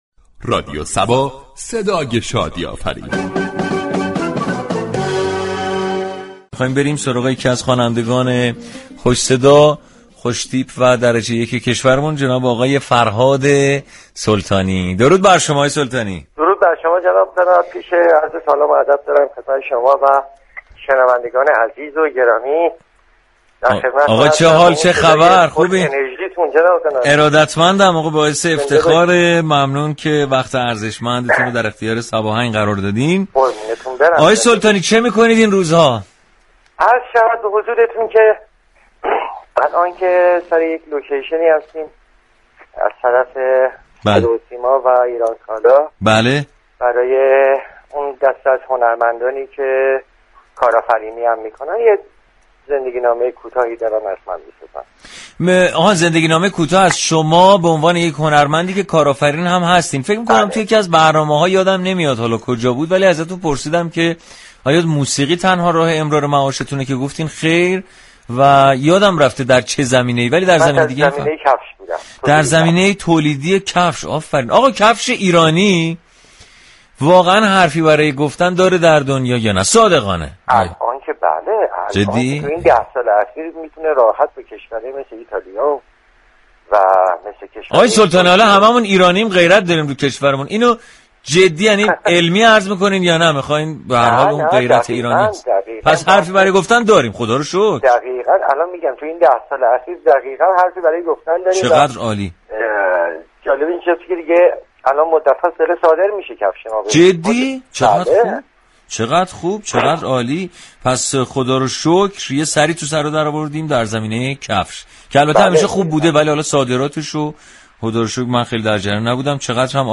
به گزارش روابط عمومی رادیو صبا، "صباهنگ"برنامه موسیقی محور رادیو صبا كه با پخش ترانه های درخواستی، فضای شادی را برای مخاطبان این شبكه ایجاد می كند، این برنامه هر روز میزبان یكی از خوانندگان خوب كشورمان می شود.